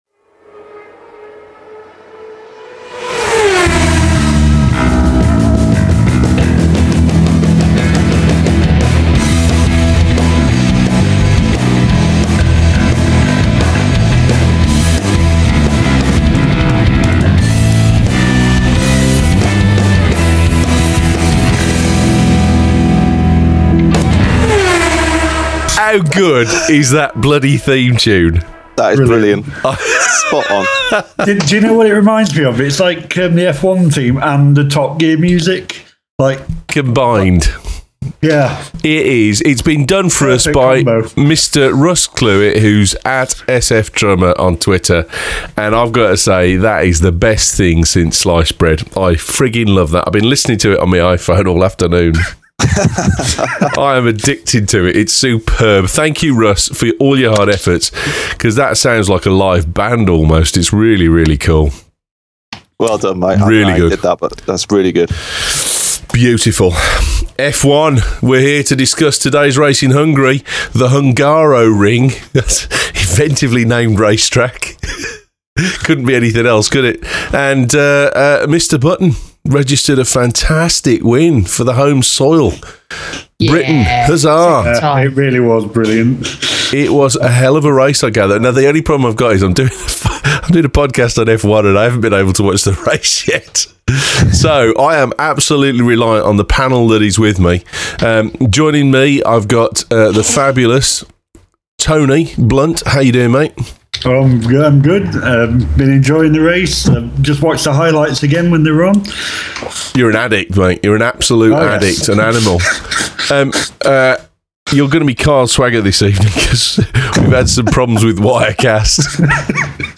The New Show from the Bagel Tech Network chatting about all things F1 and the weeks races. We publish an open chat after each week's race and voice our thoughts and feelings on the ins and outs of the race